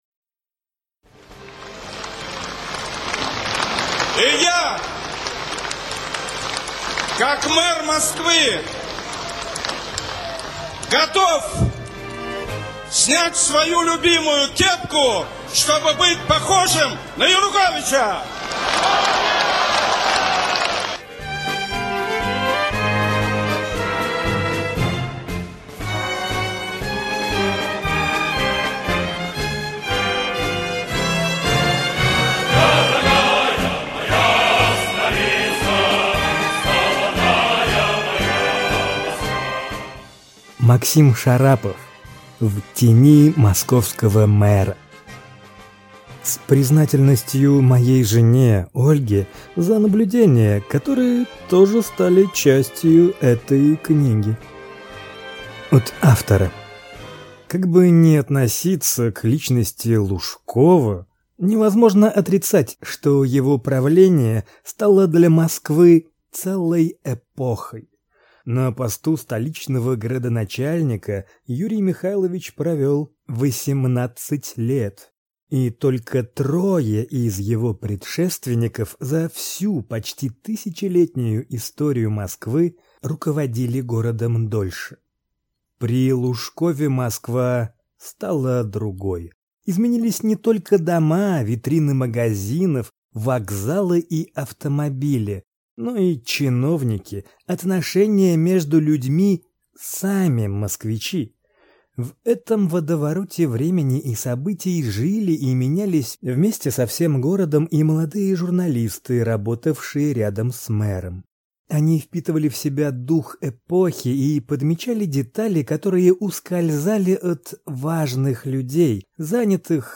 Аудиокнига В тени московского мэра | Библиотека аудиокниг